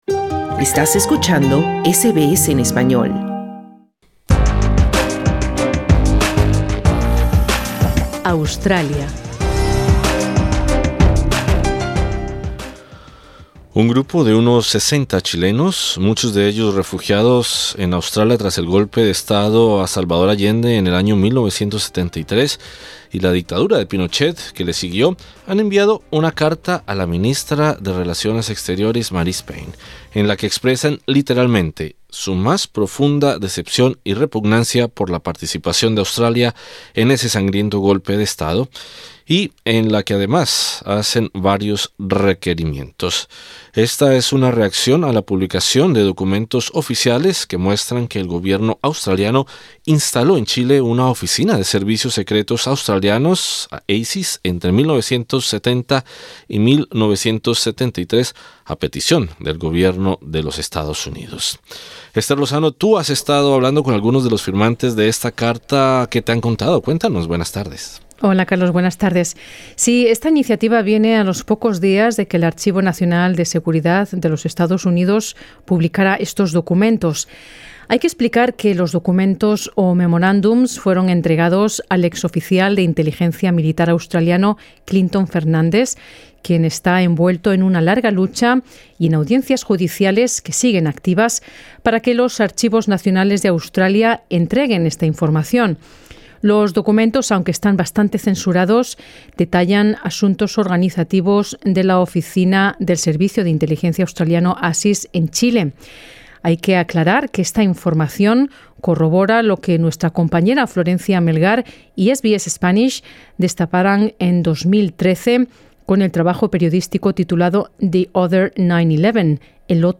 El grupo solicita a Australia que pida perdón y desclasifique documentos sobre estas operaciones secretas. SBS Spanish recoge los testimonios de algunos de los firmantes.